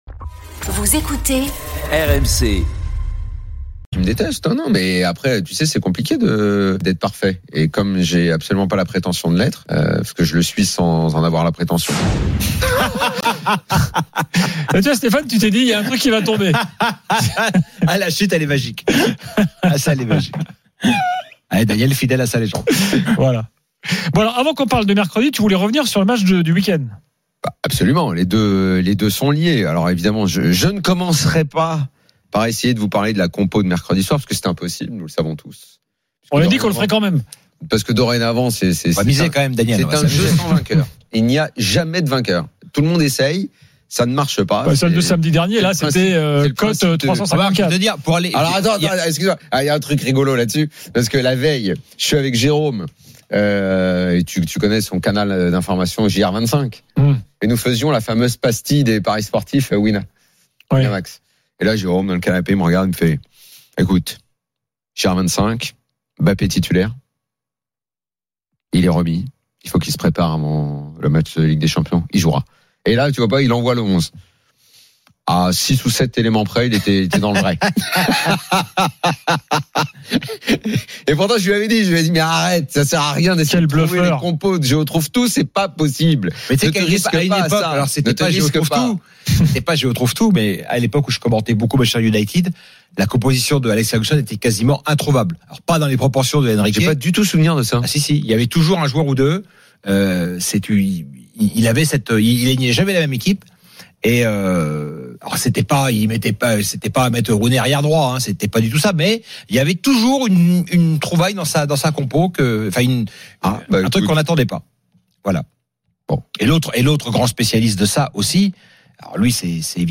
L’After foot, c’est LE show d’après-match et surtout la référence des fans de football depuis 15 ans ! Les rencontres se prolongent tous les soirs avec Gilbert Brisbois et Nicolas Jamain avec les réactions des joueurs et entraîneurs, les conférences de presse d’après-match et les débats animés entre supporters, experts de l’After et auditeurs.